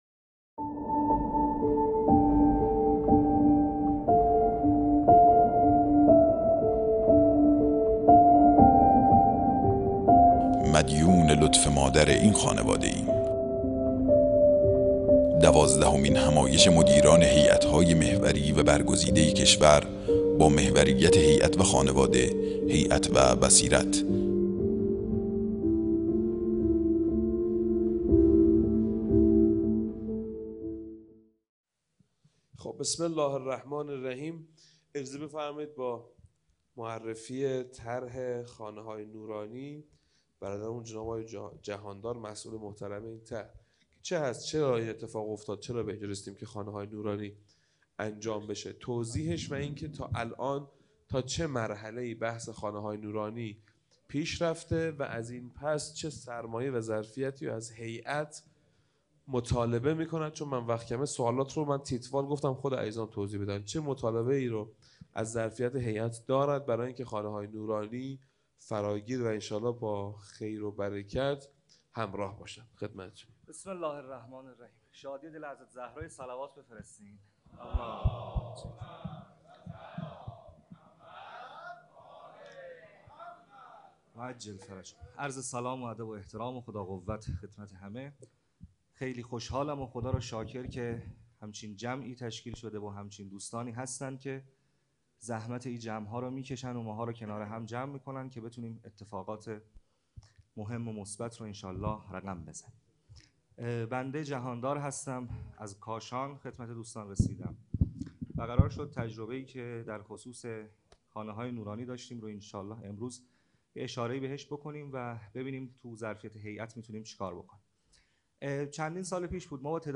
دوازدهمین همایش هیأت‌های محوری و برگزیده کشور با محوریت هیأت و خانواده، هیأت و بصیرت | شهر مقدس قم - مسجد مقدس جمکران